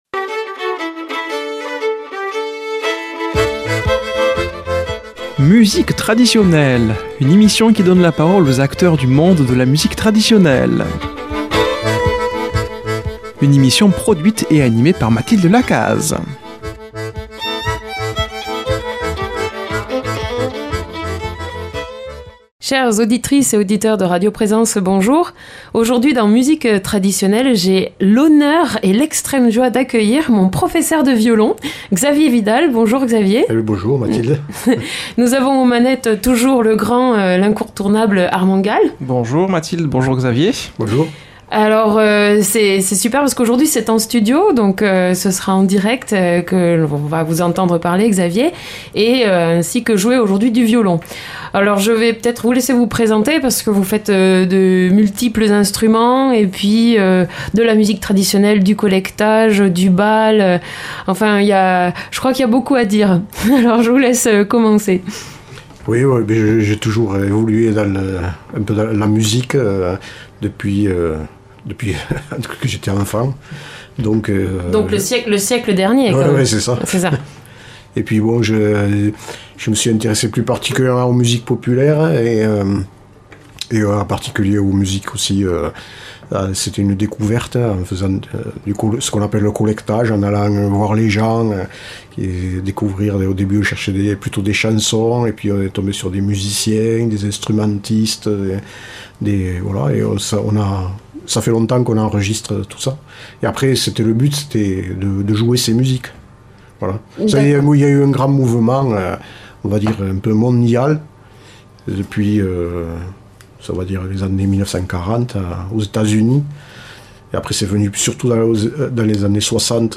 Musique Traditionelle
entretien